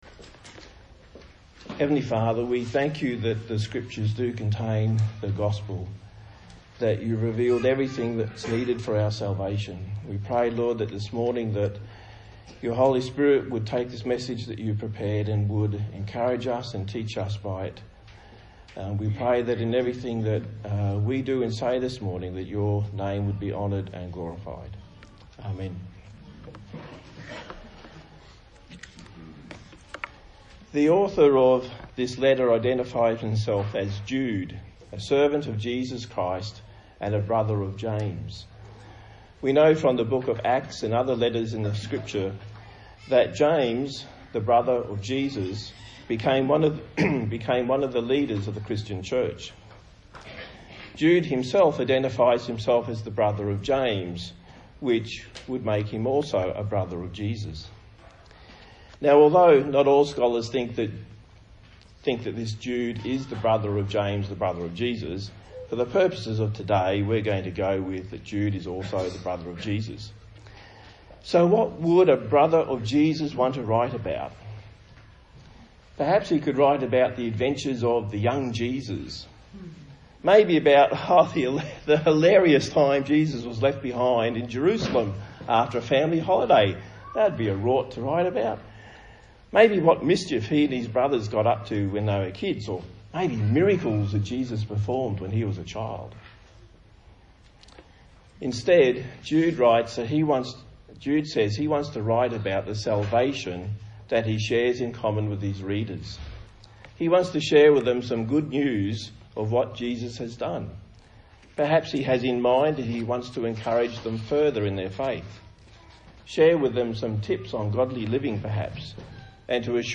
A sermon on the book of Jude. Headings: Introduction; False Teachers; Past Judgment; Current Judgment; Build Yourself Up in Faith; Practise Discernment.
Jude Service Type: Sunday Morning A sermon on the book of Jude.